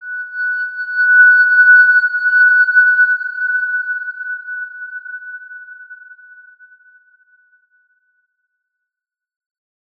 X_Windwistle-F#5-mf.wav